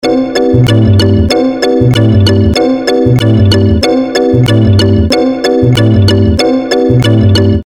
It's a nerve drum or something.
nerve-drum.mp3